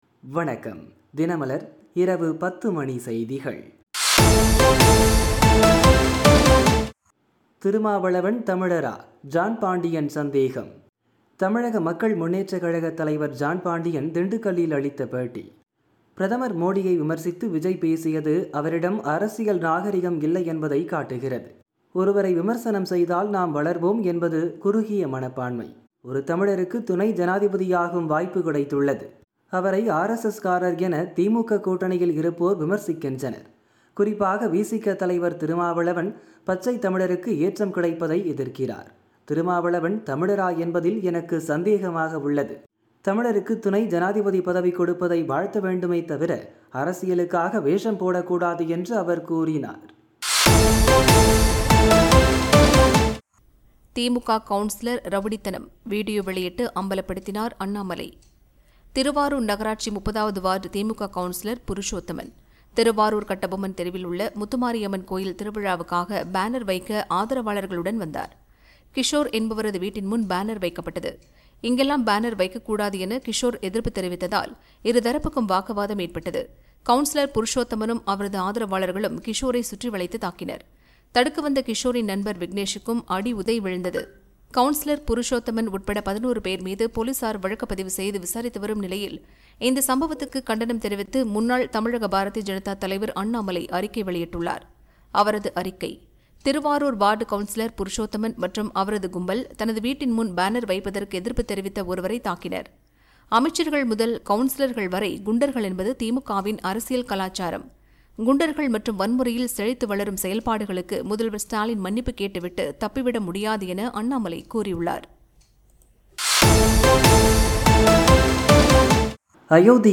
தினமலர் இரவு 10 மணி செய்திகள் - 26 August 2025